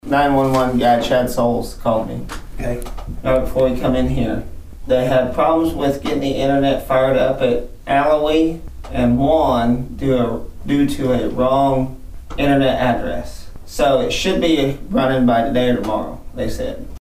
The Nowata County Commissioners met for the first time in November on Monday morning at the Nowata County Annex.
District Two Commissioner Brandon Wesson gave an update.
Wesson on More Tower Talk.mp3